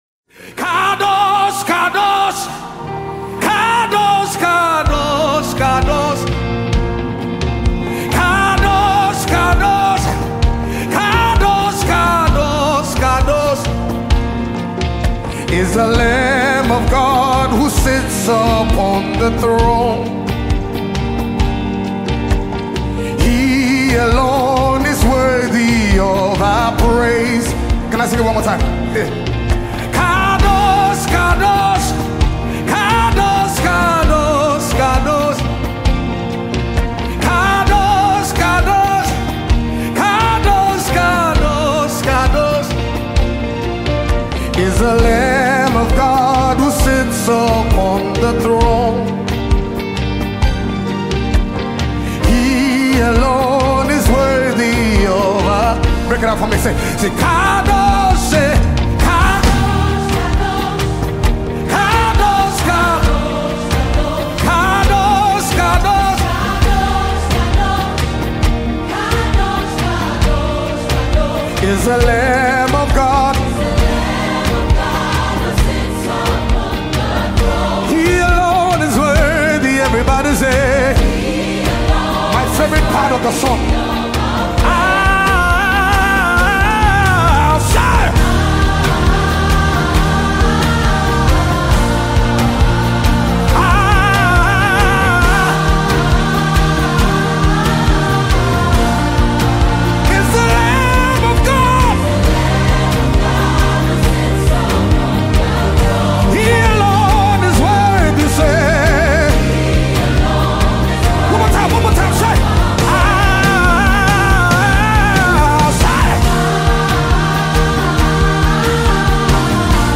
A sound of worship to the king of kings singing